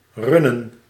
Ääntäminen
Vaihtoehtoiset kirjoitusmuodot rennen Synonyymit exploiteren Ääntäminen Tuntematon aksentti: IPA: /ˈrʏ.nə(n)/ Haettu sana löytyi näillä lähdekielillä: hollanti Käännöksiä ei löytynyt valitulle kohdekielelle.